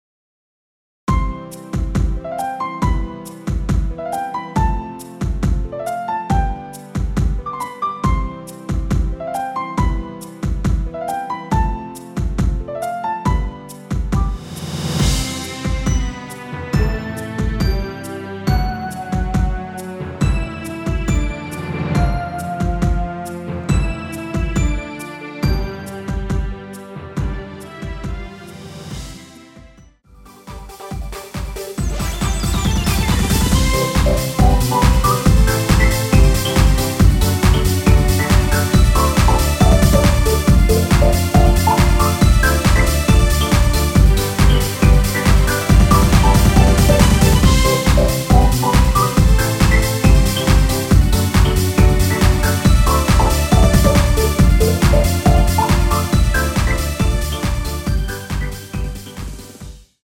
남성분이 부르실 수 있는 키의 MR입니다.(미리듣기 확인)
원키에서(-7)내린 MR입니다.
앞부분30초, 뒷부분30초씩 편집해서 올려 드리고 있습니다.